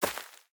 Minecraft Version Minecraft Version latest Latest Release | Latest Snapshot latest / assets / minecraft / sounds / block / hanging_roots / step5.ogg Compare With Compare With Latest Release | Latest Snapshot